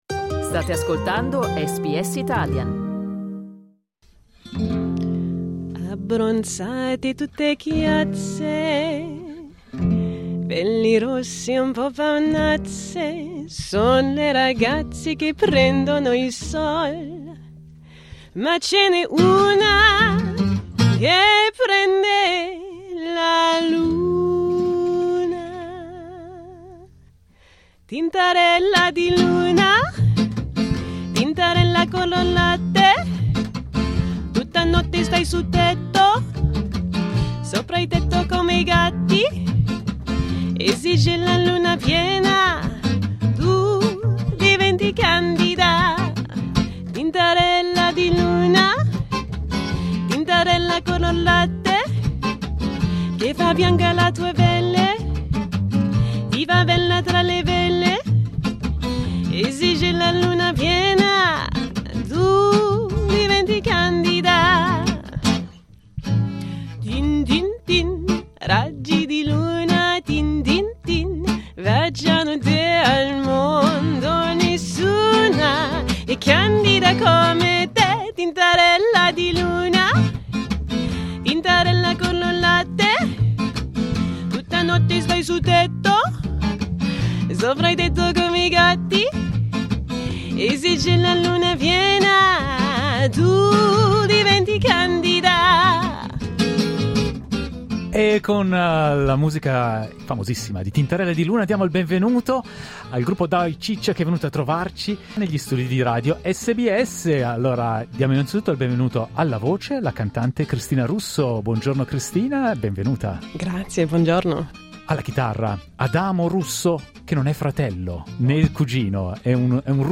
Un viaggio nella musica italiana del dopoguerra: Dai, Ciccio! negli studi di SBS
La band di Melbourne Dai, Ciccio! è venuta a trovarci negli studi di SBS per raccontarci la loro storia e parlarci della loro musica.
Dai, Ciccio! si dedica agli anni d'oro della musica italiana e italo-amnericana degli anni '50 e '60, rivisti in chiave jazz e swing.